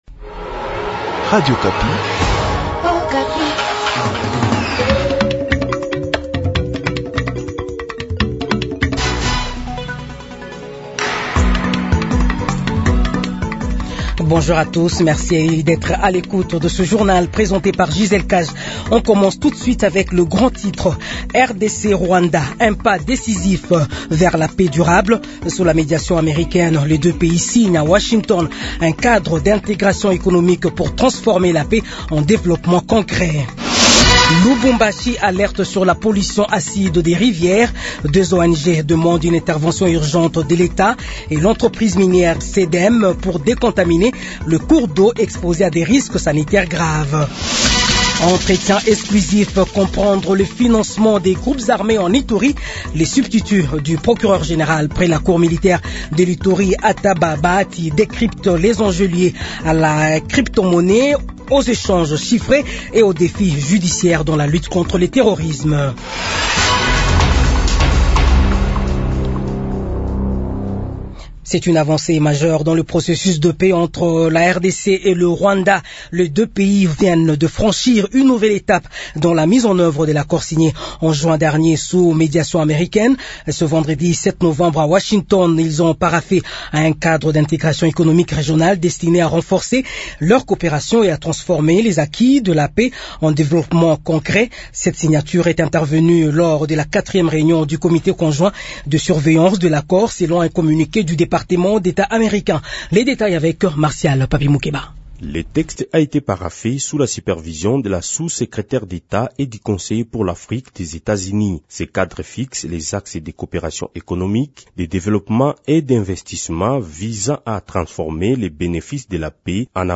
Edition du journal de 15 heures de ce samedi 08 novembre 2025